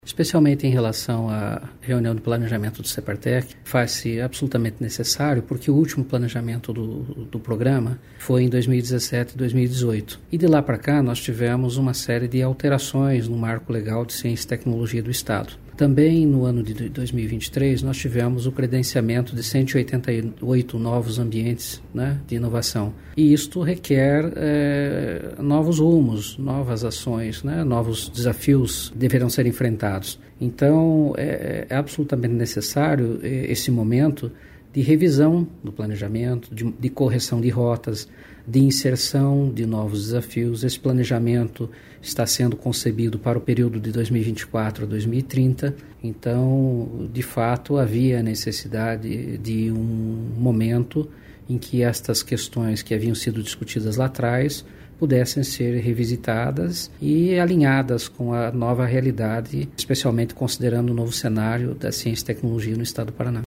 Sonora do secretário em exercício da Ciência, Tecnologia e Ensino Superior, Jamil Abdanur Júnior, sobre a primeira reunião de planejamento estratégico do Separtec de 2024